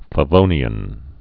(fə-vōnē-ən)